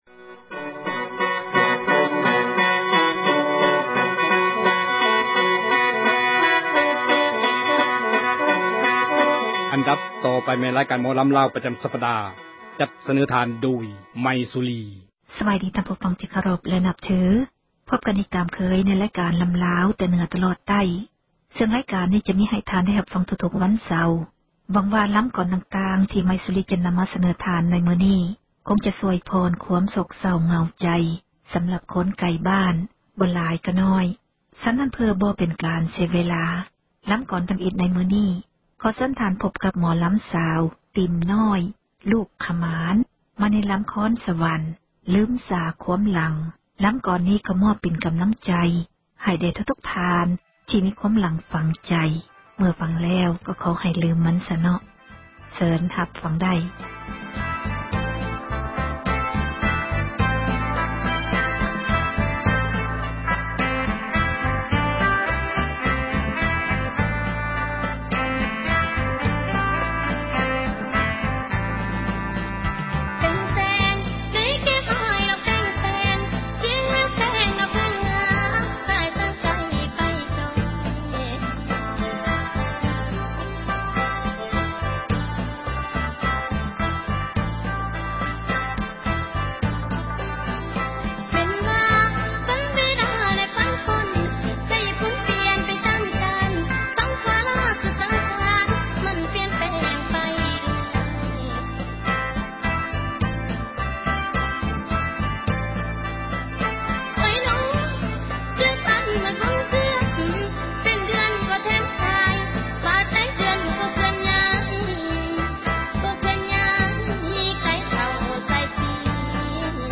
ໝໍລໍາ